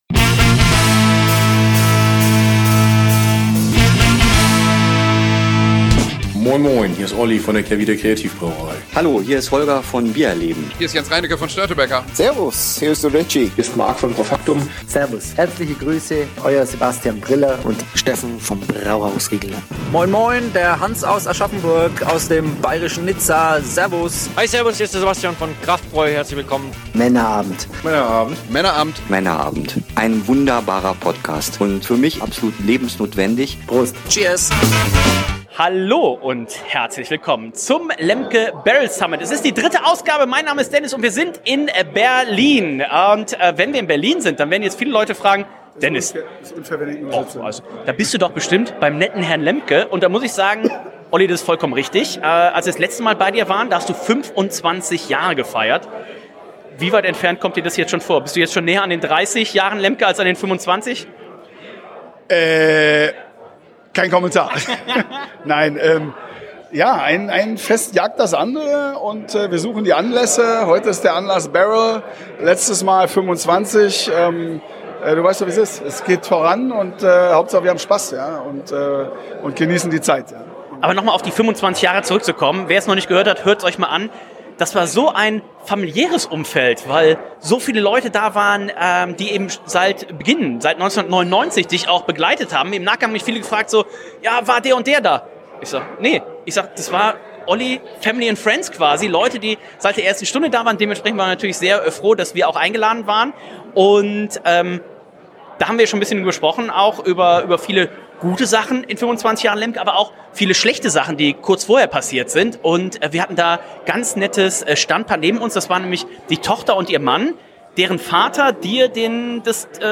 Der wahrscheinlich beste Event rund um das Thema „Holzfass“ in Deutschland, in diesem Jahr noch größer , länger und besser als je zuvor. Freut euch auf spannende Eindrücke von vor Ort und i nteressante Gesprächspartner.